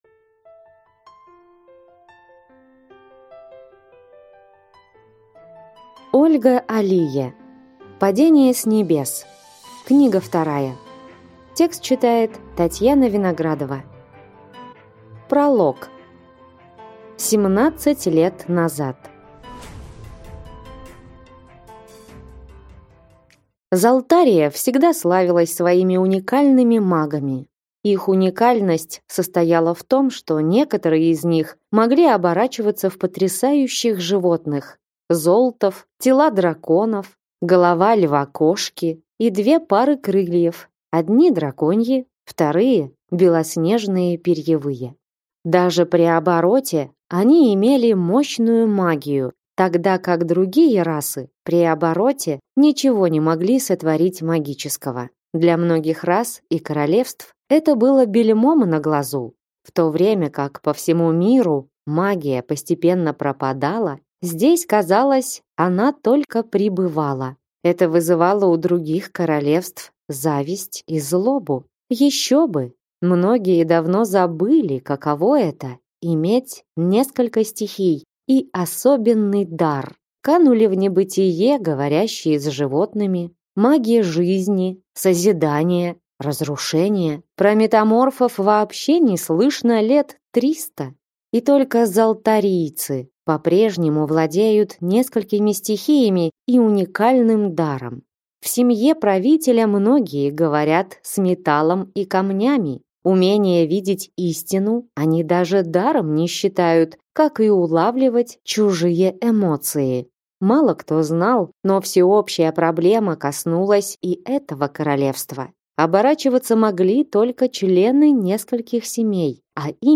Аудиокнига Падение с небес. Книга 2